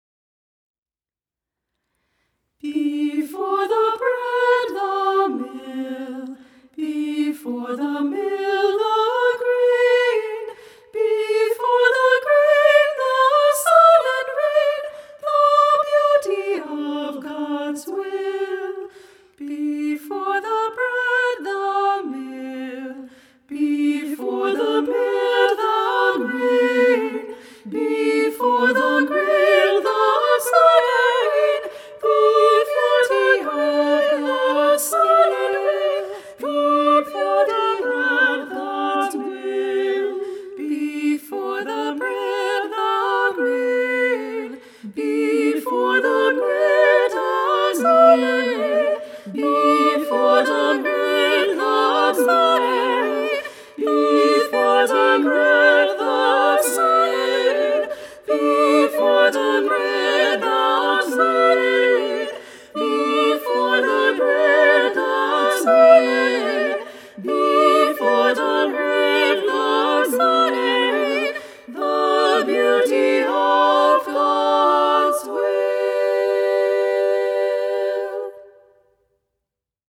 SA or SSAA a cappella